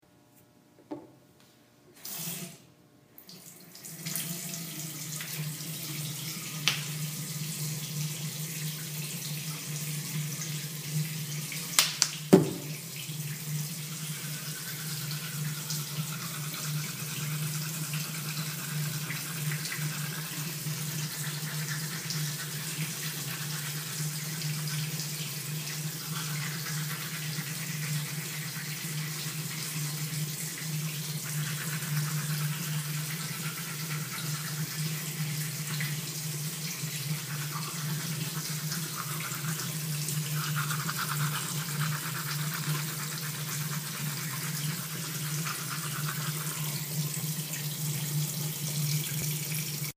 Field Recording #4
Water running, snapping the lid close on my toothpaste then putting it on the counter, brushing my teeth